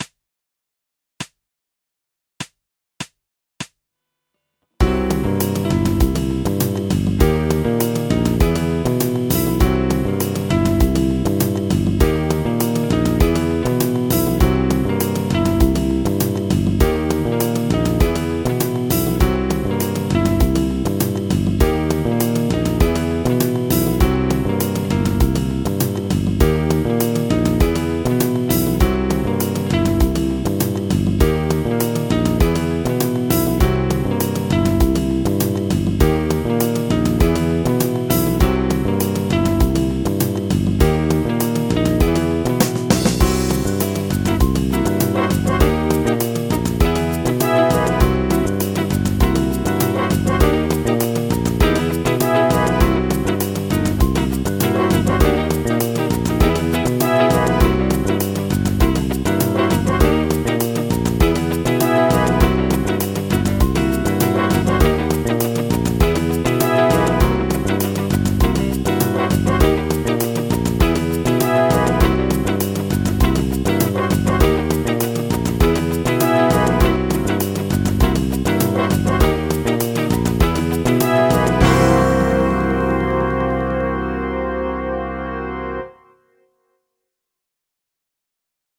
ハンガリアン・スケール ギタースケールハンドブック -島村楽器